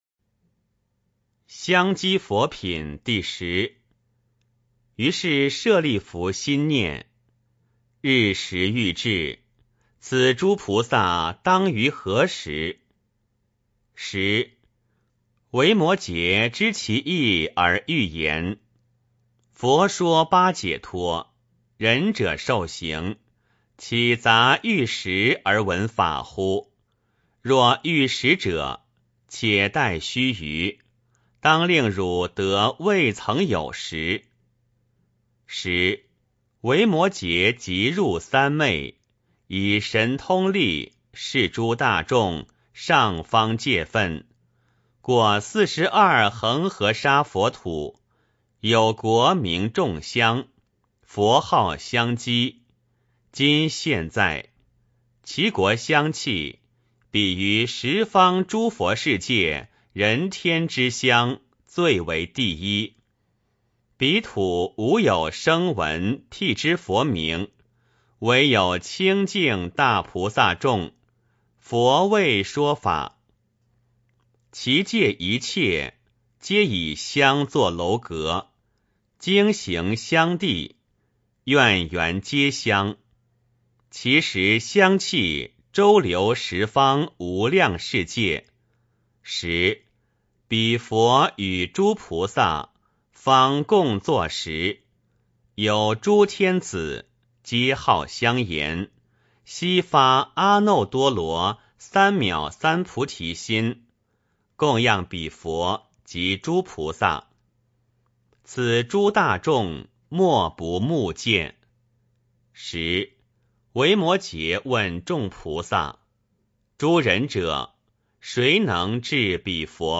维摩诘经-香积佛品第十 - 诵经 - 云佛论坛